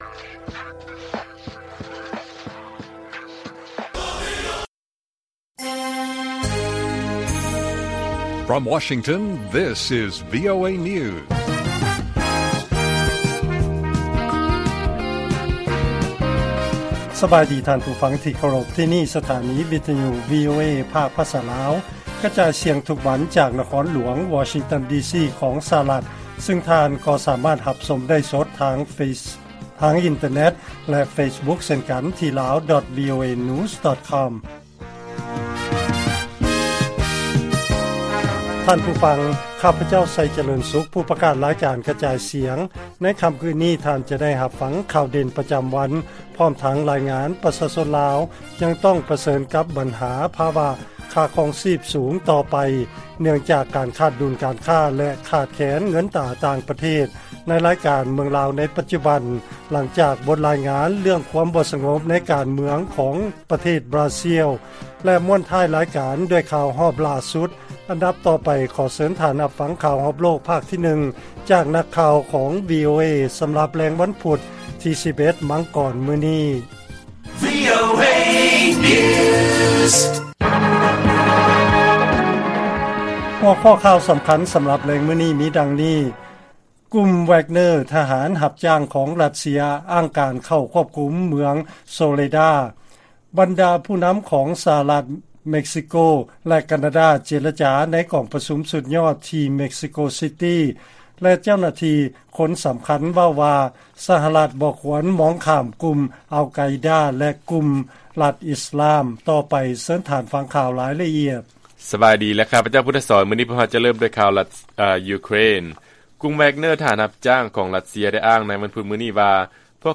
ວີໂອເອພາກພາສາລາວ ກະຈາຍສຽງທຸກໆວັນ, ຫົວຂໍ້ຂ່າວສໍາຄຫນໃນມື້ນີ້ມີ: 1. ທະຫານຮັບຈ້າງຂອງຣັດເຊຍອ້າງວ່າ ຄວບຄຸມເມືອງໂຊເລດາ, 2. ຜູ້ນຳຂອງ ສະຫະລັດ ເມັກຊິໂກ ແລະ ການາດາ ເຈລະຈາ ໃນກອງປະຊຸມສຸດຍອດ, ແລະ 3. ເຈົ້າໜ້າທີ່ຄົນສຳຄັນເວົ້າວ່າ ສະຫະລັດ ບໍ່ຄວນມອງຂ້າມກຸ່ມ ອາລ-ໄກດາ ແລະ ລັດອິສລາມ.